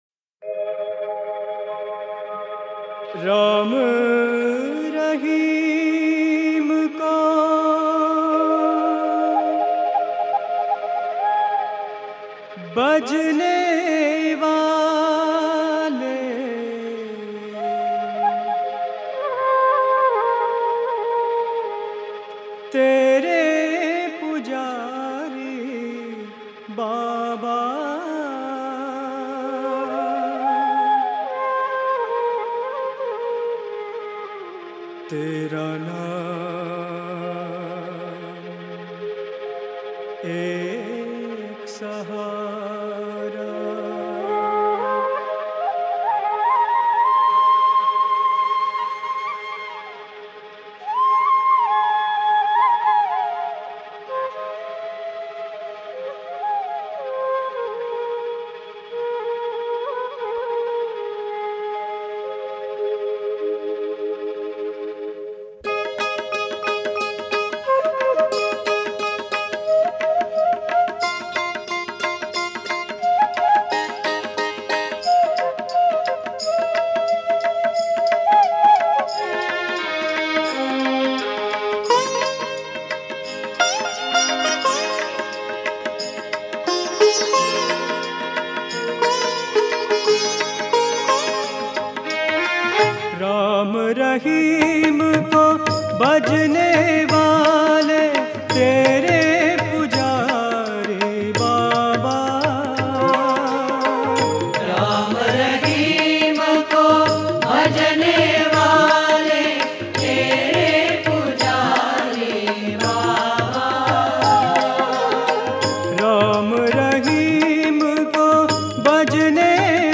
Home | Bhajan | Bhajans on various Deities | Sarva Dharma Bhajans | 32 RAM RAHIM KO BHAJANEWALE